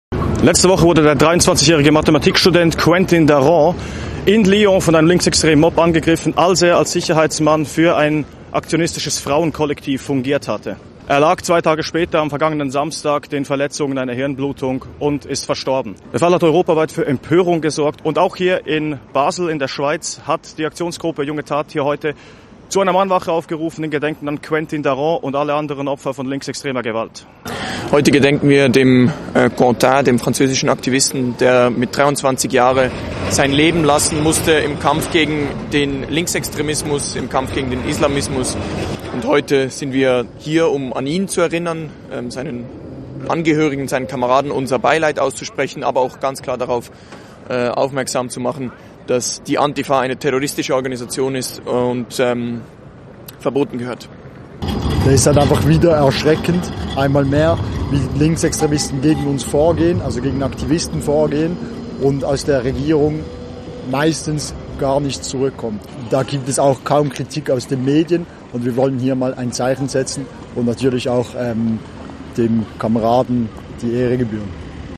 Mahnwache in Basel: „Die Antifa ist eine Terrororganisation“